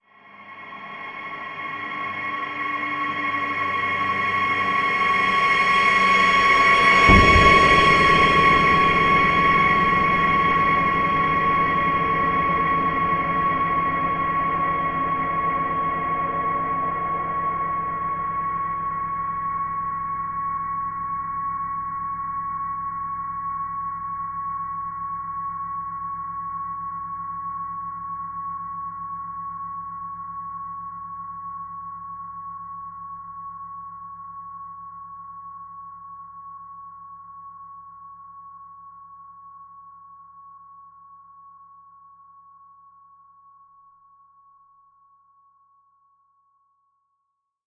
Звуки скримера, неожиданности
Продолжительный напряженный звук в фильмах ужасов